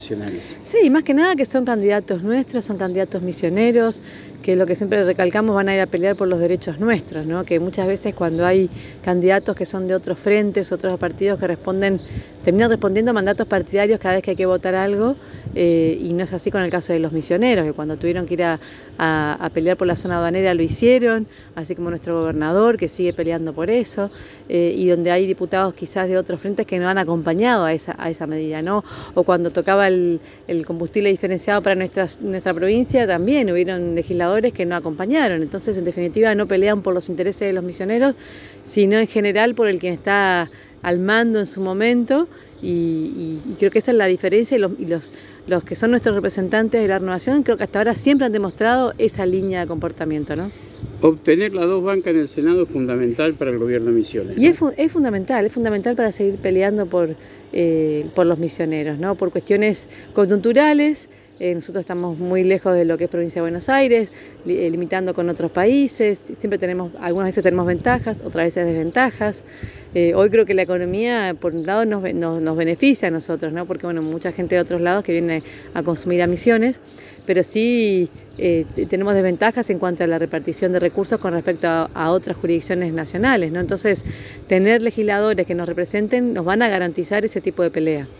En diálogo exclusivo con la ANG la intendente de Apóstoles ,María Eugenia Safrán, manifestó que la principal característica de los candidatos a legisladores nacionales del Frente Renovador en Innovación Federal es que son misioneros, de los misioneros y van a responder a Misiones y no al poder de turno.
María Eugenia Safrán Intendente de Apóstoles